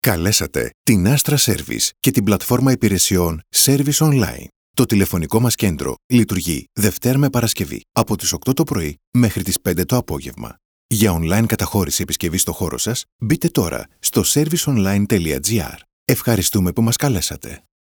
Commercieel, Natuurlijk, Vertrouwd, Warm, Zakelijk
Telefonie